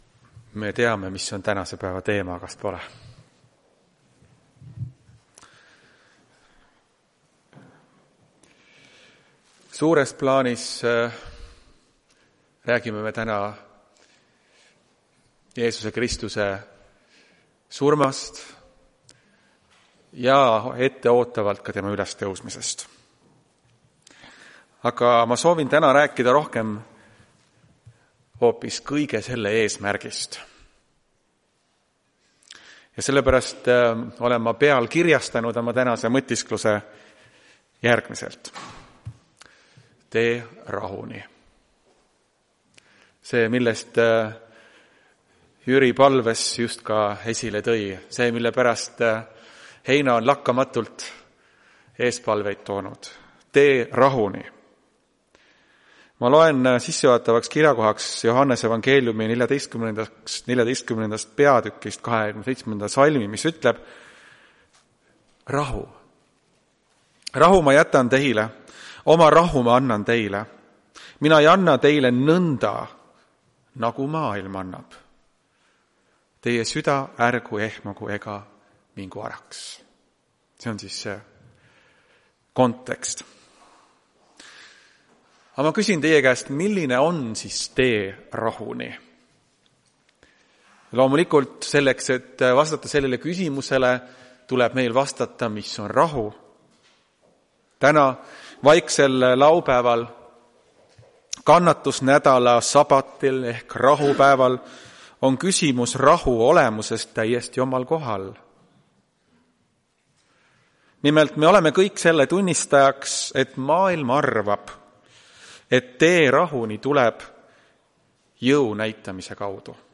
Tartu adventkoguduse 04.04.2026 teenistuse jutluse helisalvestis.
Jutlused